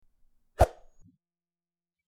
Swish
Swish.mp3